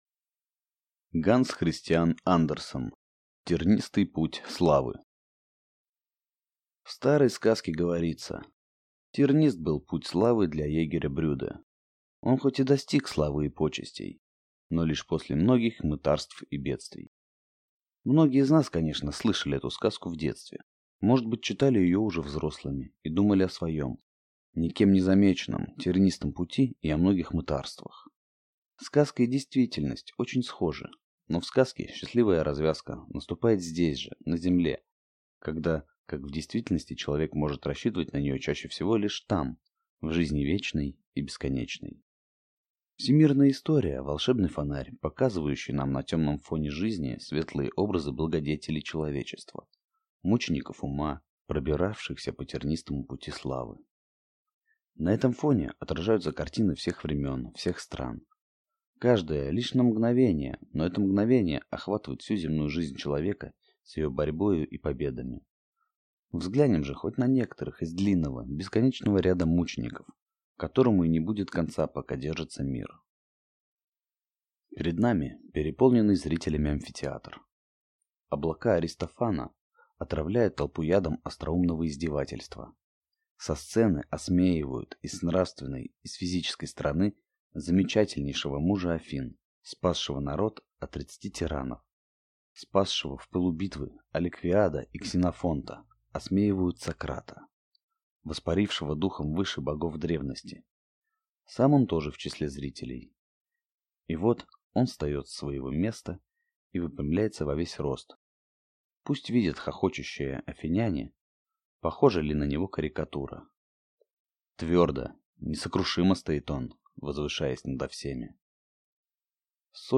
Аудиокнига Тернистый путь славы | Библиотека аудиокниг
Прослушать и бесплатно скачать фрагмент аудиокниги